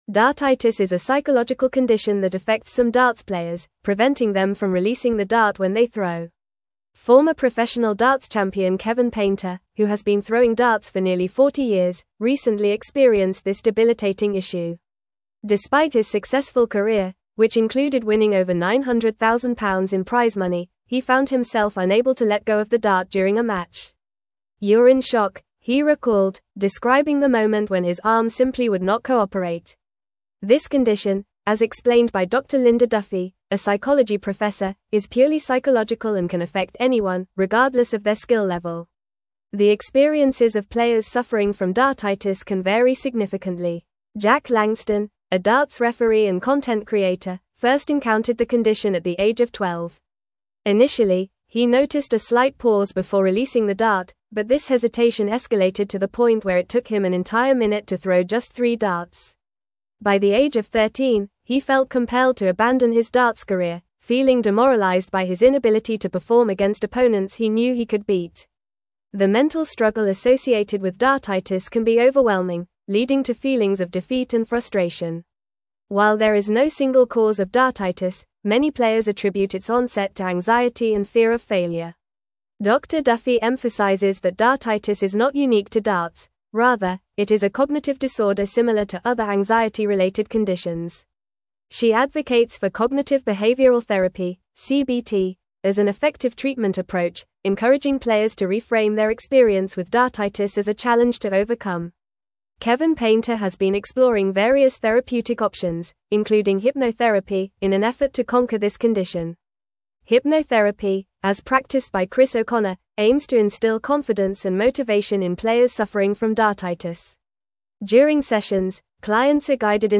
Fast
English-Newsroom-Advanced-FAST-Reading-Overcoming-Dartitis-Athletes-Mental-Health-Challenges.mp3